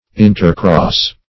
intercross - definition of intercross - synonyms, pronunciation, spelling from Free Dictionary
Intercross \In"ter*cross`\, n.